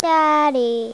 Daddy Sound Effect
daddy.mp3